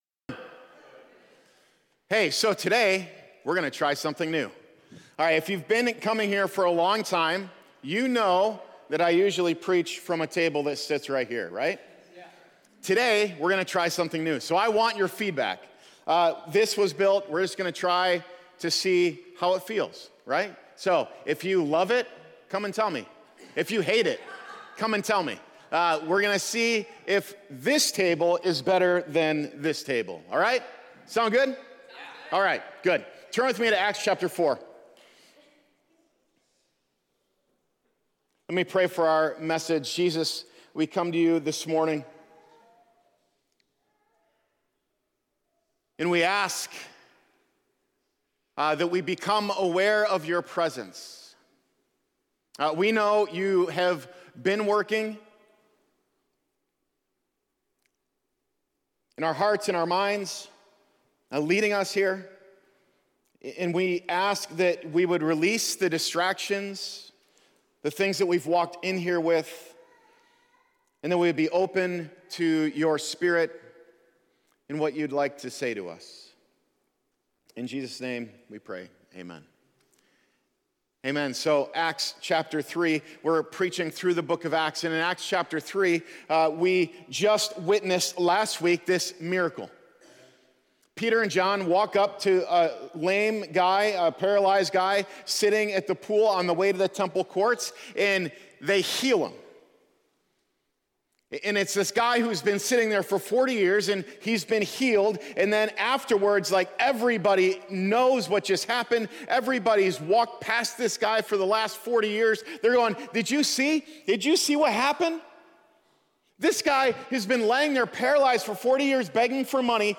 Religion Sunday Service Renew Church Christianity Content provided by Renew Church.